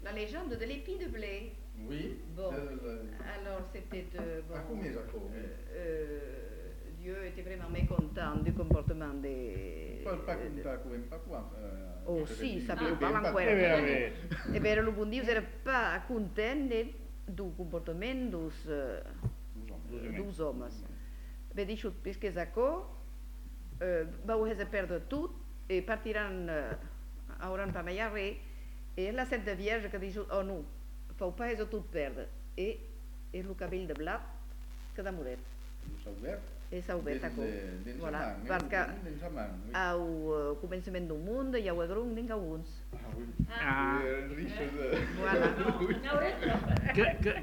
Lieu : Uzeste
Genre : conte-légende-récit
Effectif : 1
Type de voix : voix de femme
Production du son : parlé
Classification : récit légendaire